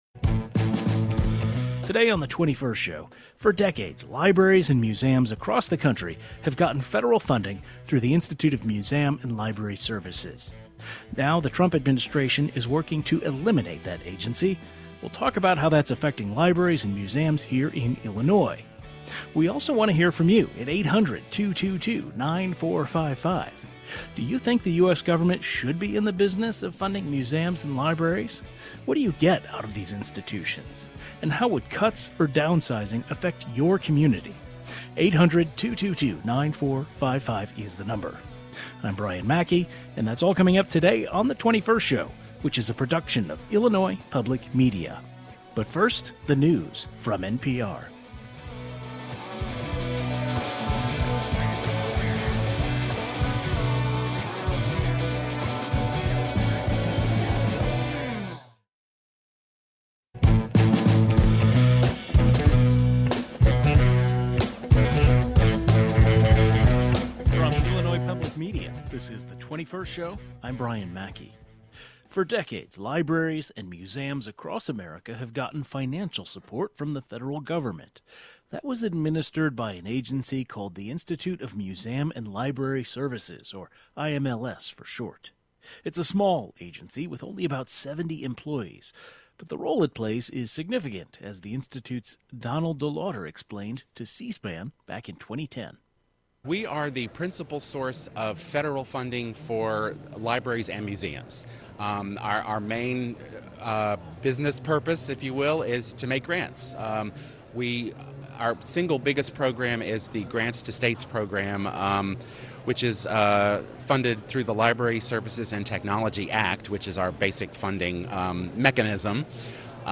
A panel of experts overseeing library services and and museums weigh in.